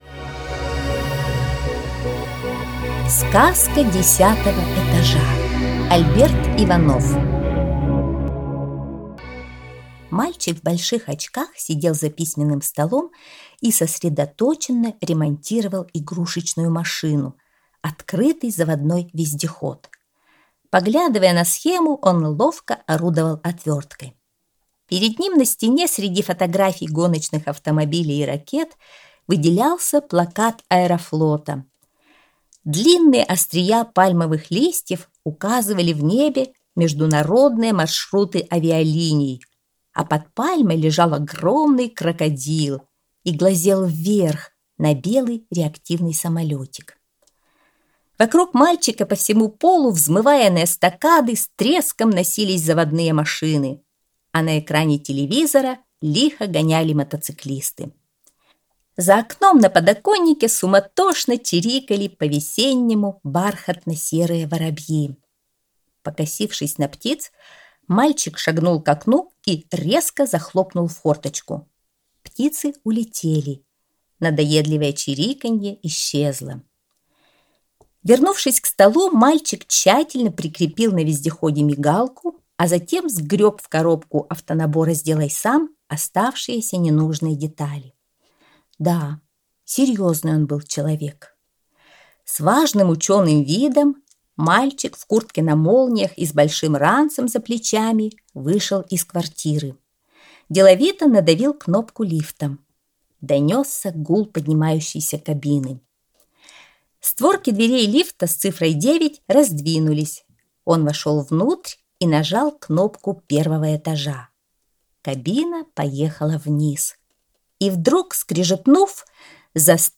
Сказка десятого этажа - аудиосказка Иванова А. Жил любознательный мальчик, обожавший механизмы и гаджеты. Как-то раз в своём девятиэтажном доме он решил нажать в лифте загадочную десятую кнопку, которой там быть не должно.